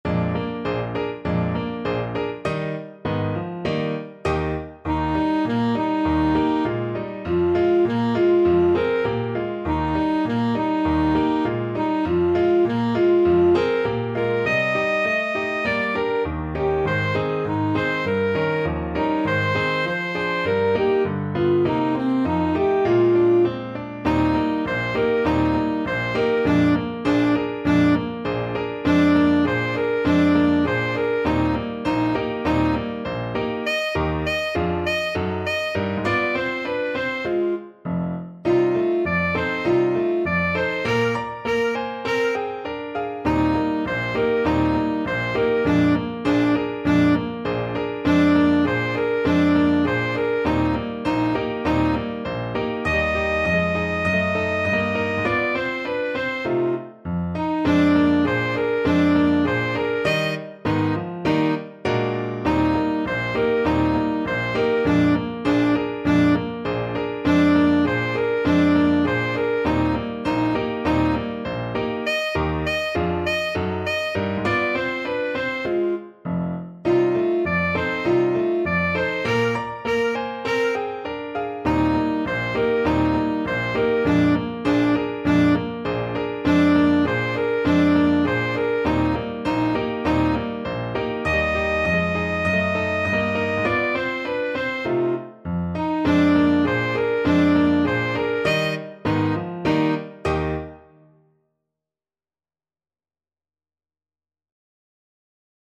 Alto Saxophone version
Moderato =c.100
2/2 (View more 2/2 Music)
Pop (View more Pop Saxophone Music)